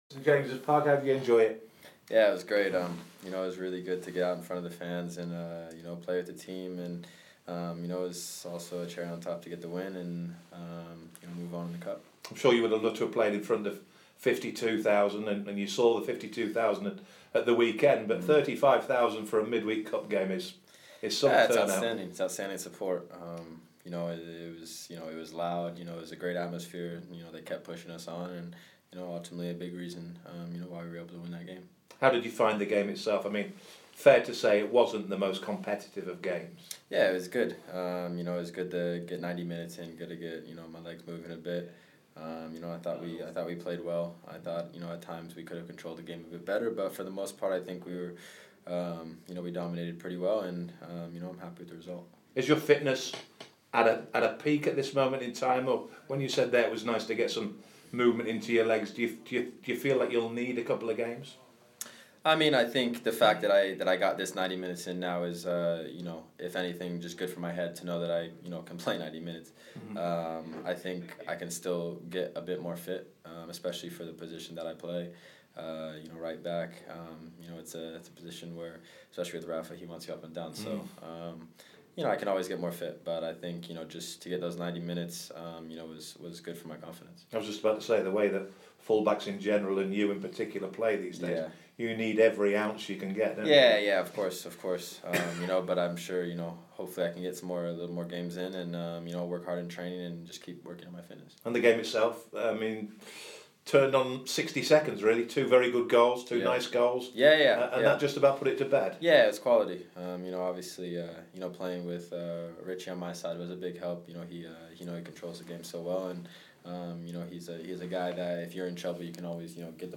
DeAndre Yedlin spoke to BBC Newcastle after helping Newcastle United into the last 16 of the EFL Cup.